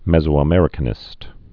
(mĕzō-ə-mĕrĭ-kə-nĭst, mĕs-)